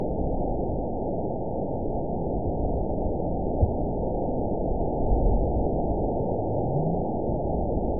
event 912563 date 03/29/22 time 12:24:00 GMT (3 years, 1 month ago) score 9.65 location TSS-AB04 detected by nrw target species NRW annotations +NRW Spectrogram: Frequency (kHz) vs. Time (s) audio not available .wav